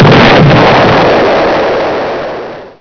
Explosio
explosio.wav